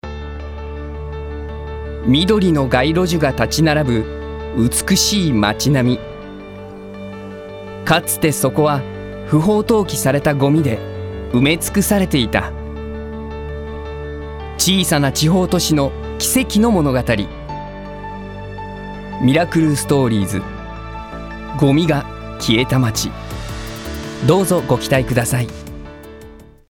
声を聴く Voice Sample
5.環境ドキュメンタリー編